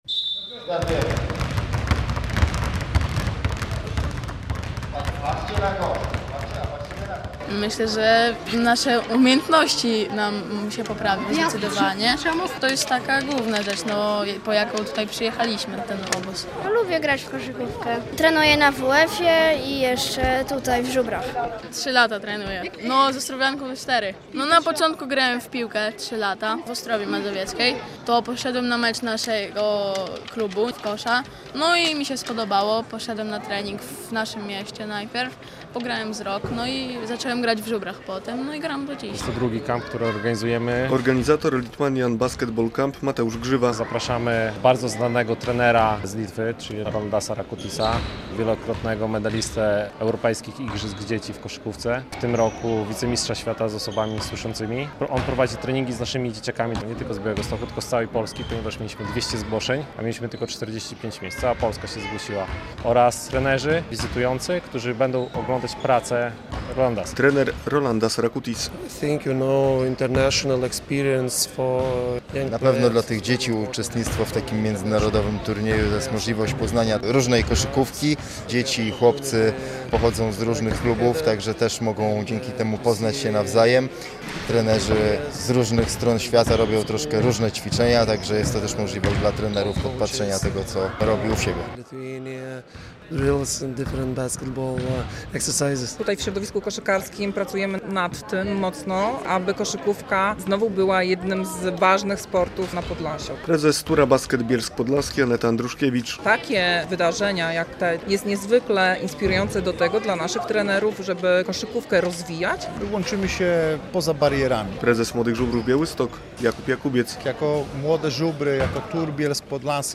Lithuanian Basketball Camp w Białymstoku - relacja